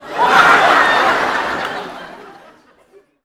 Audience Laughing-07.wav